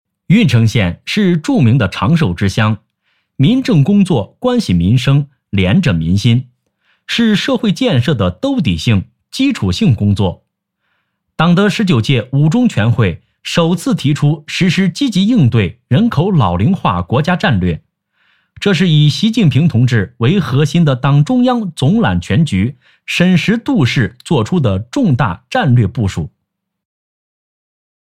【男80号新闻】郓城县
【男80号新闻】郓城县.mp3